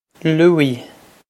Loo-ee
This is an approximate phonetic pronunciation of the phrase.